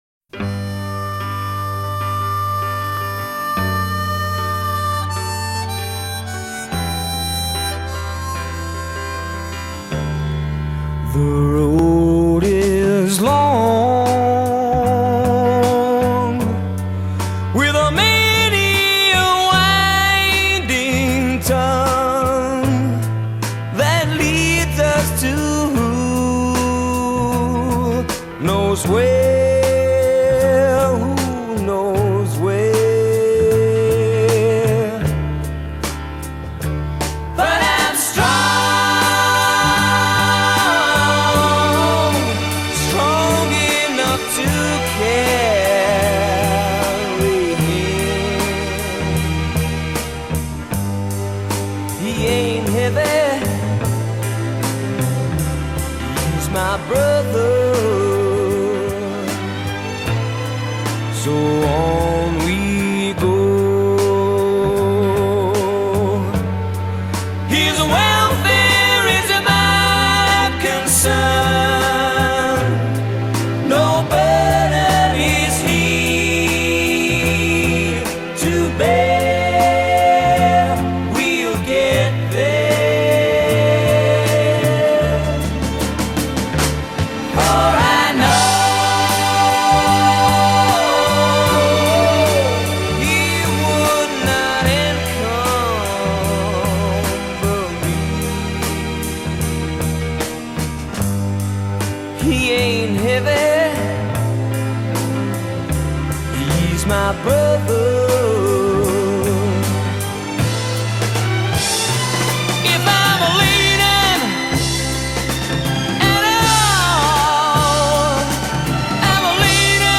soft rock
سافت راک